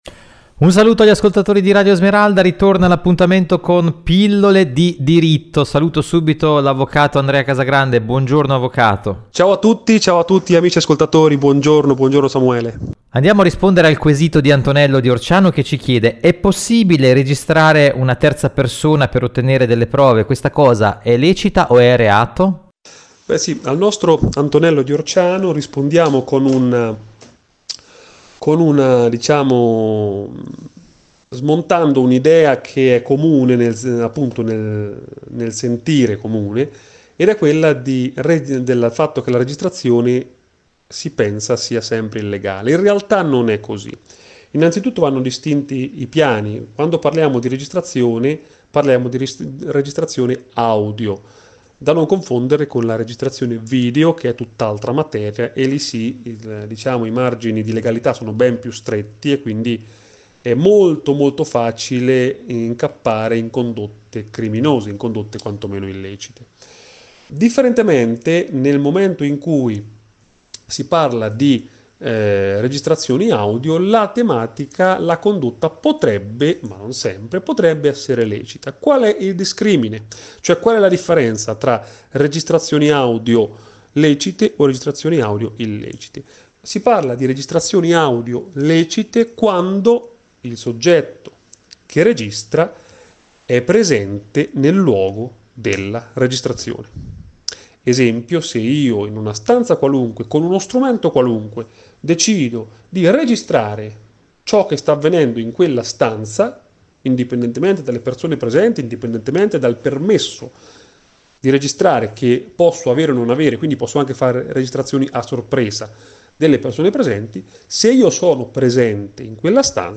Ogni due settimane, il giovedi alle ore 10,40 nei nostri studi